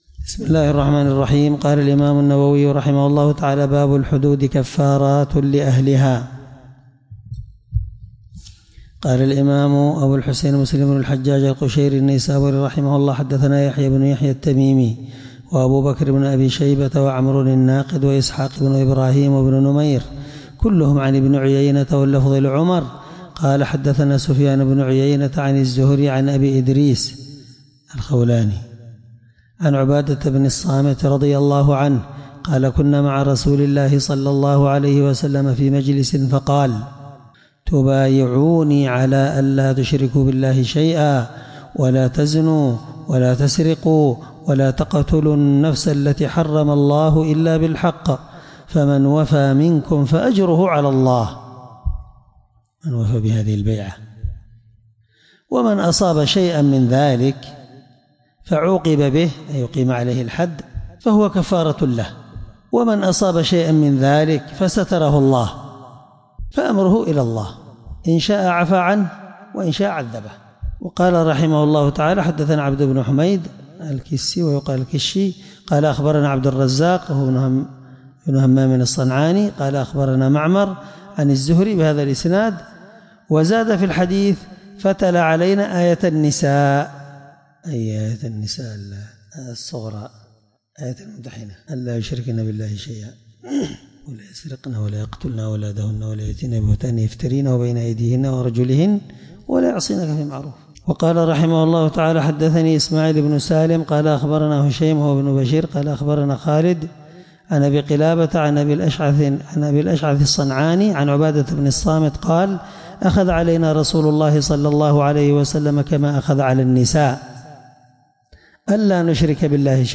الدرس19من شرح كتاب الحدود حديث رقم(1709) من صحيح مسلم